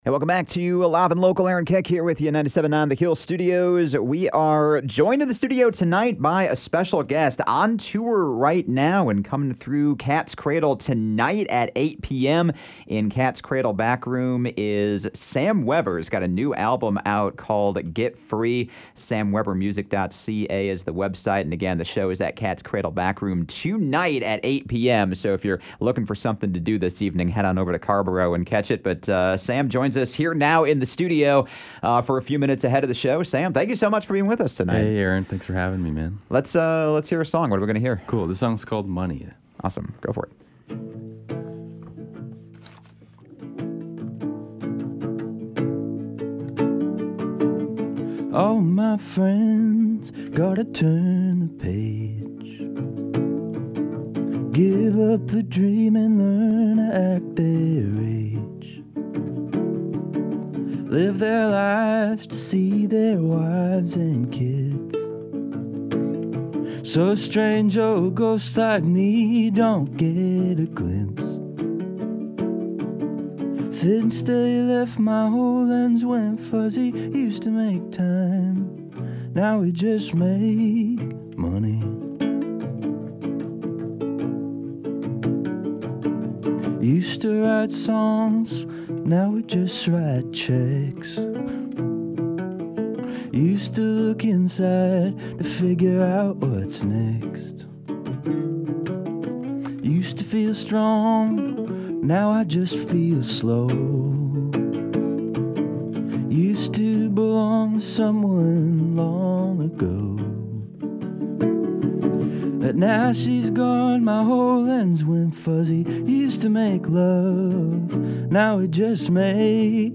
He also performed two songs from the new album